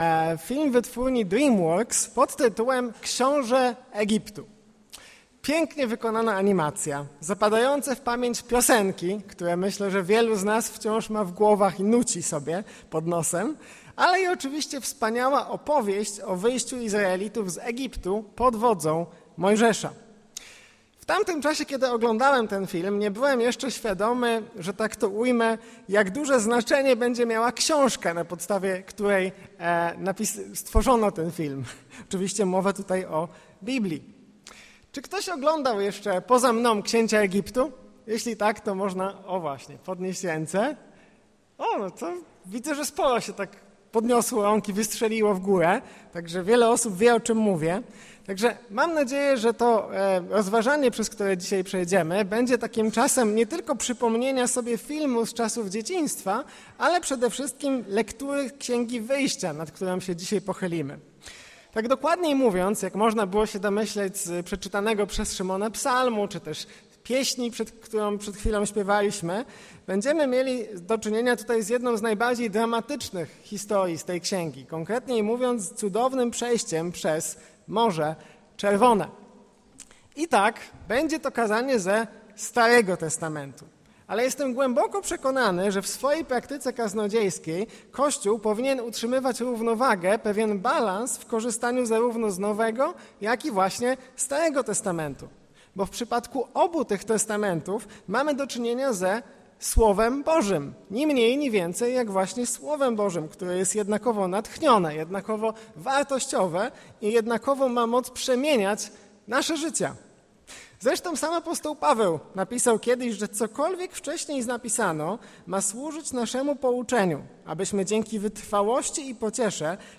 Kaznodzieja